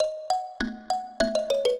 mbira
minuet8-4.wav